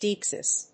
• / ˈdaɪksɪs(米国英語)